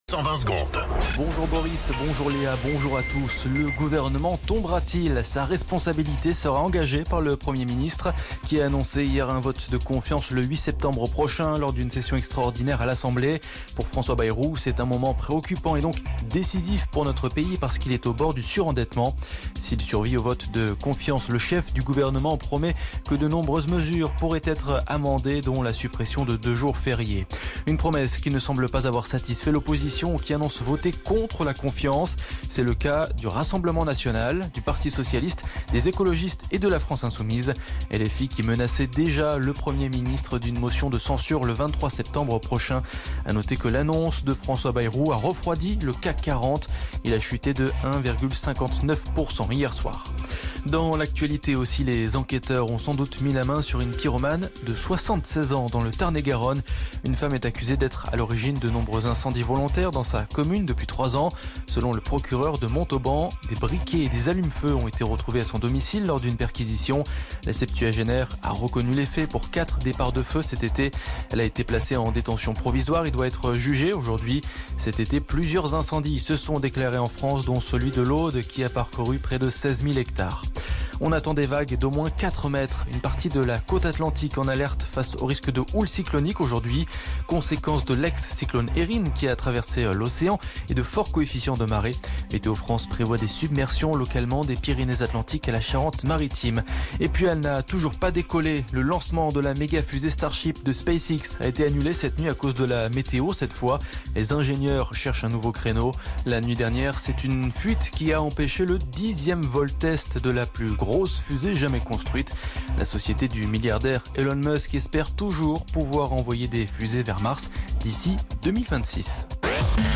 Flash Info National 26 Août 2025 Du 26/08/2025 à 07h10 .